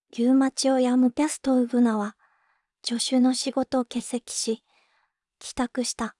voicevox-voice-corpus / ROHAN-corpus /WhiteCUL_かなしい /ROHAN4600_0025.wav